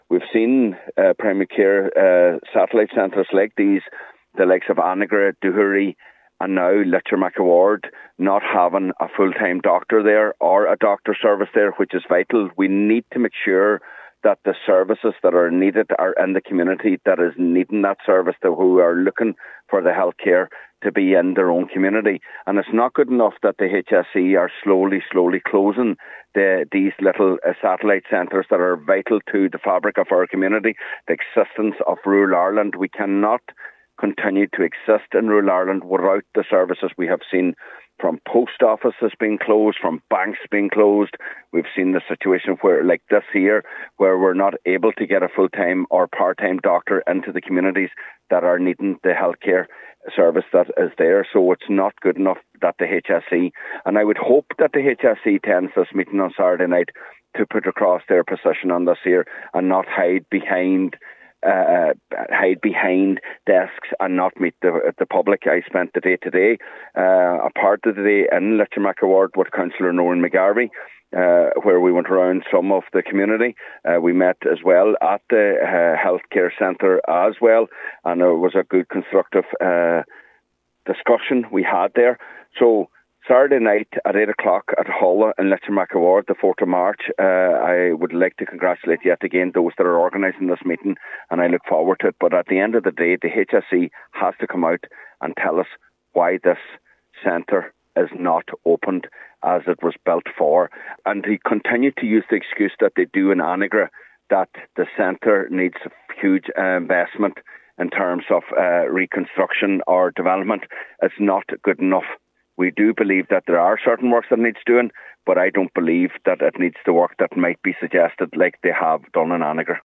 Councillor Michéal Choilm MacGiolla Easbuig says the area cannot afford to lose this vital service: